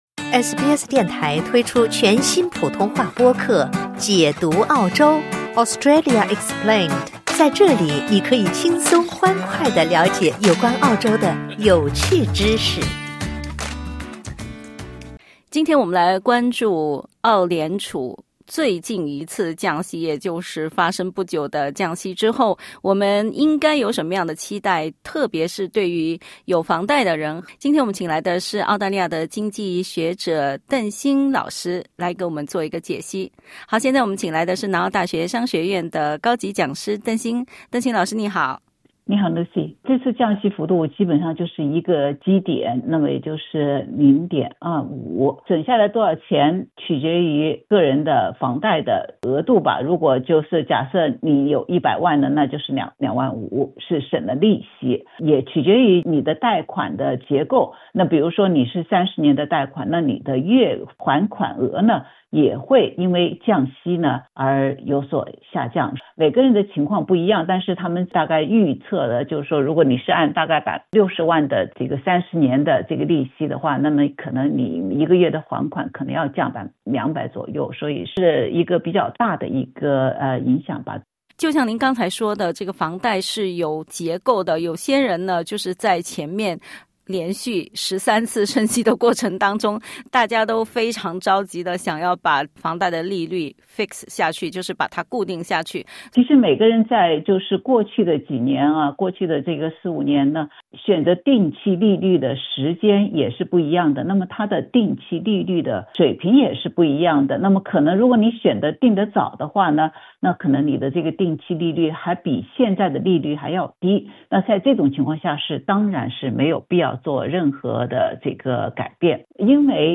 点击音频收听详细采访 本节目仅为嘉宾观点 欢迎下载应用程序SBS Audio，订阅Mandarin。